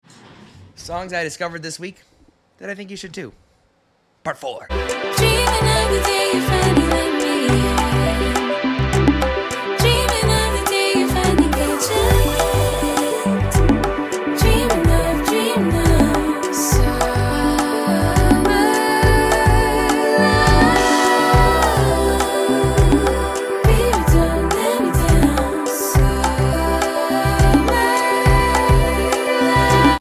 Great production, great harmonies, great time